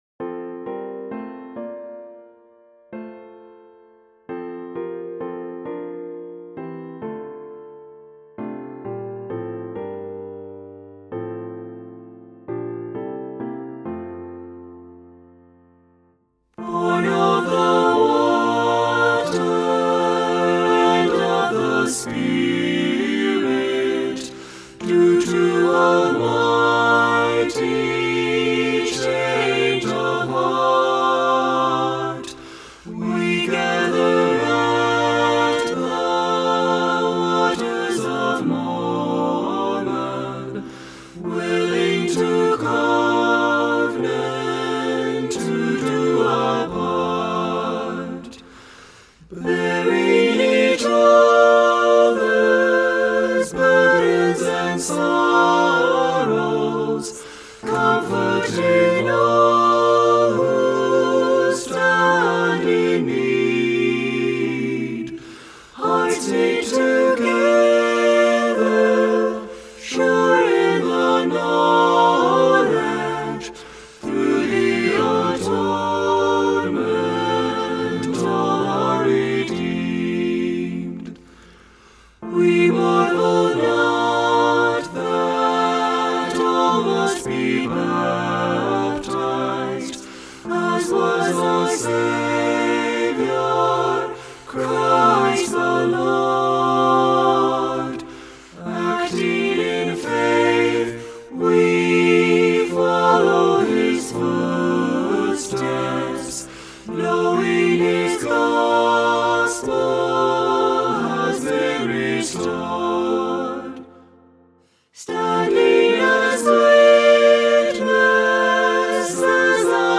SATB Hymn
SATB Traditional Hymn